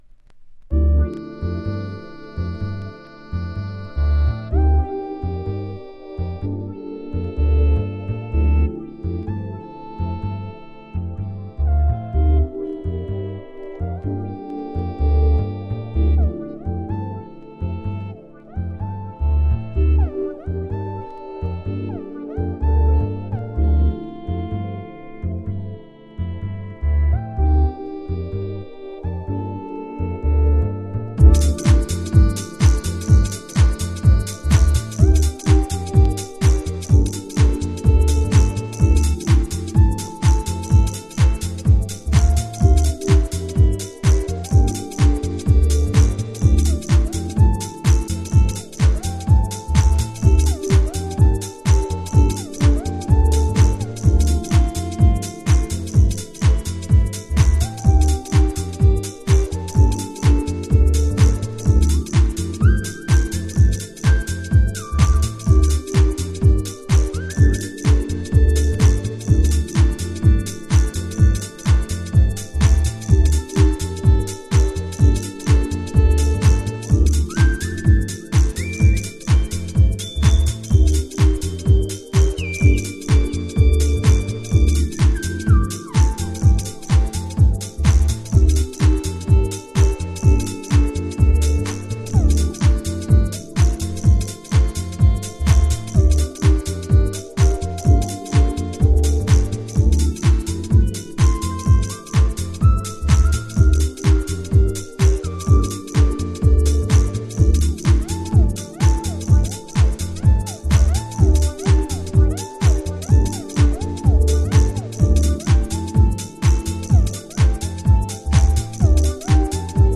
アコースティックなプロダクションに移行しながらも、RAW & LOWな音像で前のめりにグルーヴするあたりは変わらずです。
House / Techno